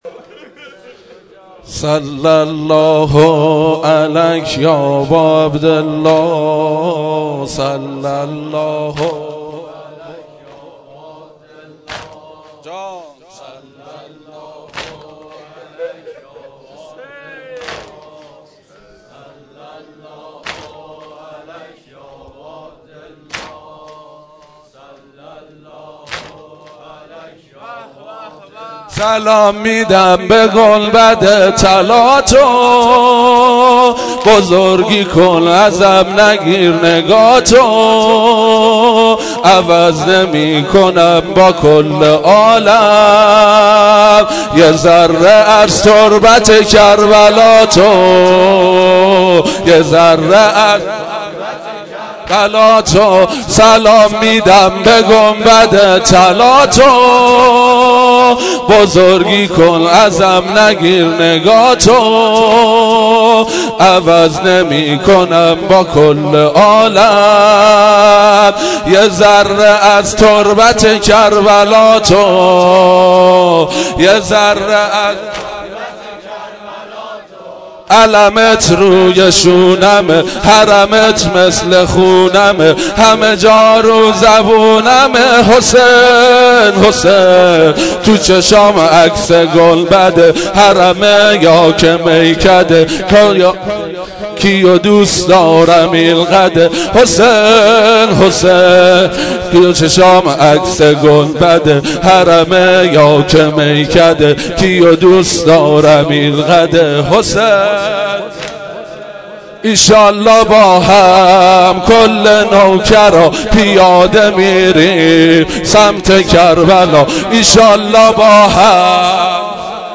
نوحه شلاقی
شب چهارم محرم